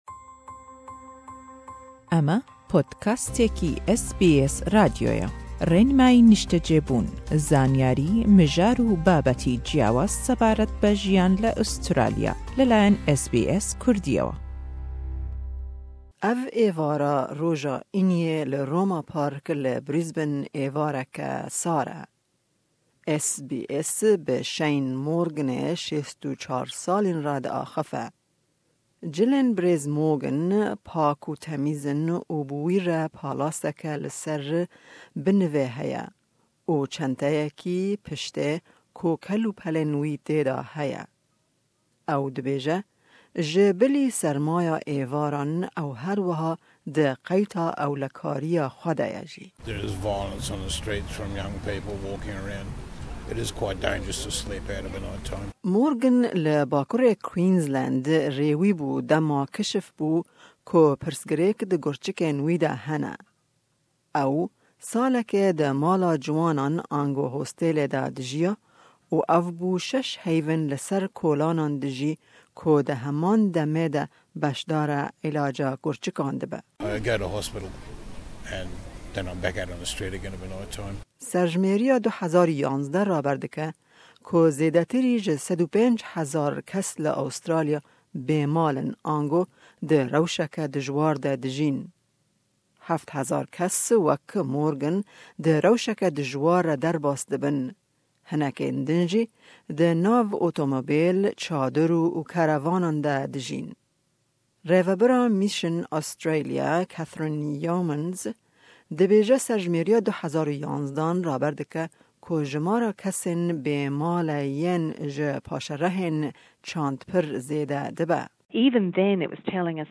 Bi boneya Hefteya Hoshmendiya Bê-Malan/Homelessness Awareness Week SBS diche parka Roma li bajarê Brisbane û bi chend kesên li ser kolanan dijîn re diaxafe.